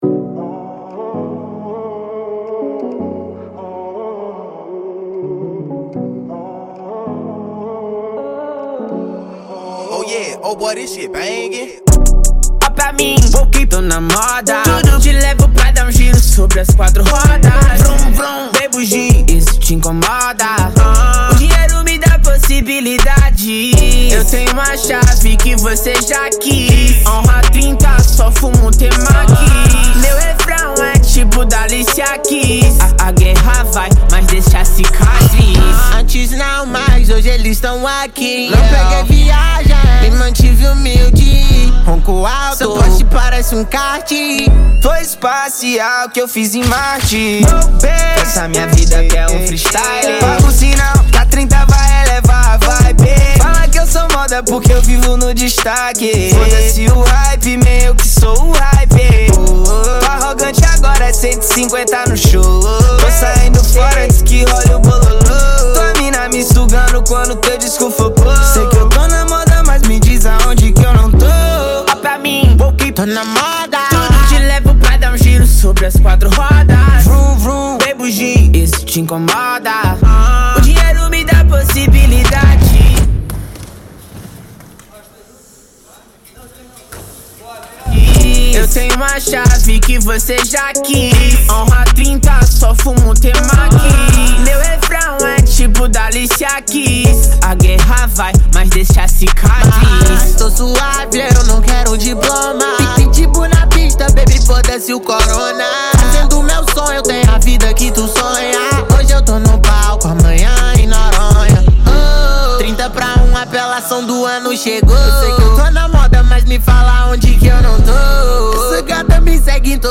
2024-04-08 19:00:45 Gênero: Trap Views